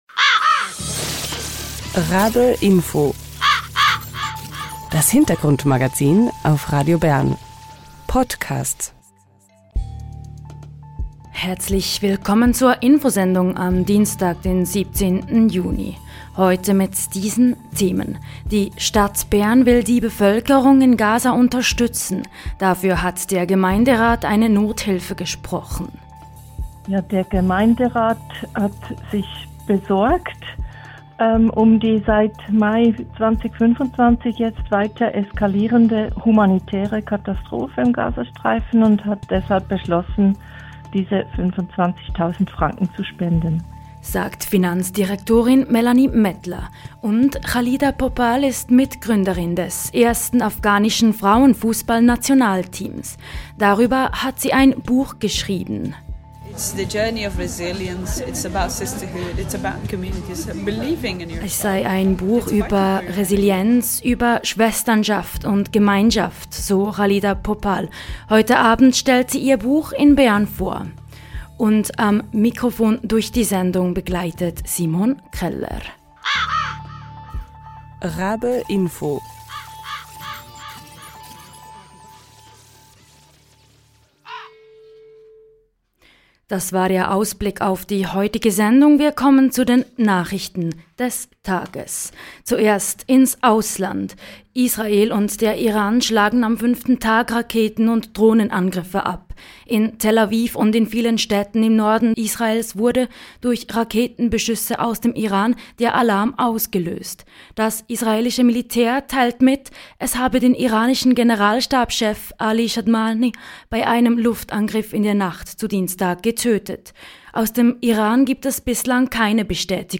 Die Stadt Bern will die Bevölkerung in Gaza unterstützen. Dafür hat der Gemeinderat eine Nothilfe gesprochen. Die Finanzdirektorin Melanie Mettler im Interview bei RaBe-Info.